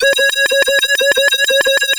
OSCAR 14 D2.wav